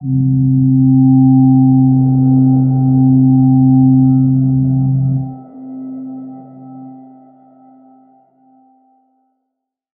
G_Crystal-C4-pp.wav